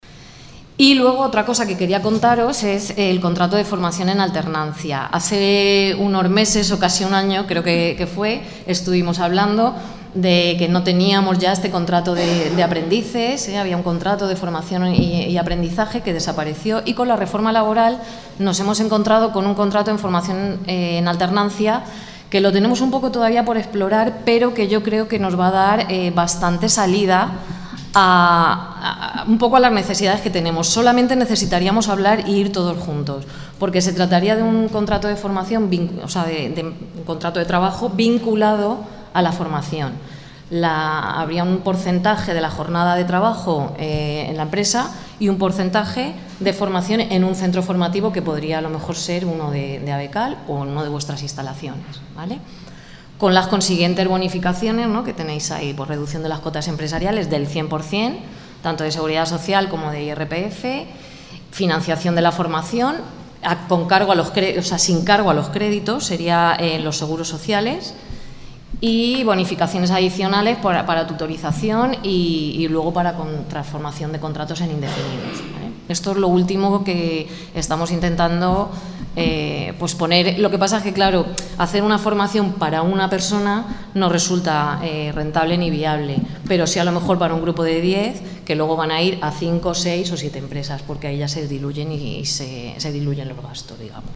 Interesante charla debate sobre empleabilidad y formación del sector del calzado